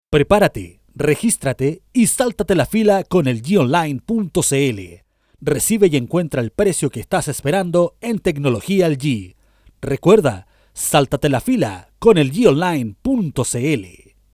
chilenisch
Sprechprobe: Werbung (Muttersprache):